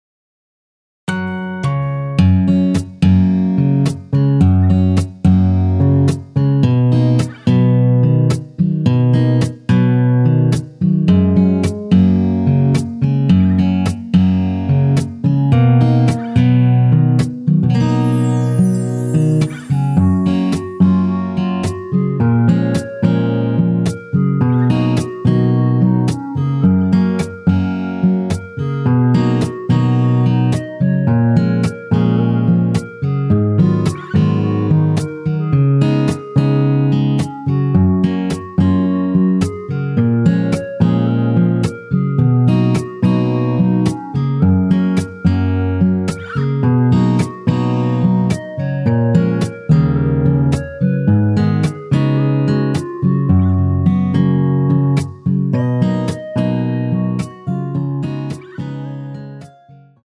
엔딩이 페이드 아웃이라 마지막 음~ 2번 하고 엔딩을 만들어 놓았습니다.(일반MR 미리듣기 참조)
F#
◈ 곡명 옆 (-1)은 반음 내림, (+1)은 반음 올림 입니다.
앞부분30초, 뒷부분30초씩 편집해서 올려 드리고 있습니다.
중간에 음이 끈어지고 다시 나오는 이유는